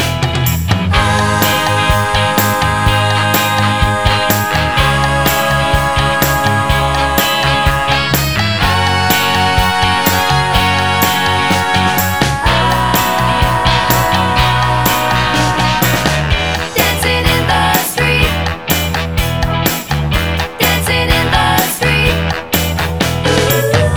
No Backing Vocals Soul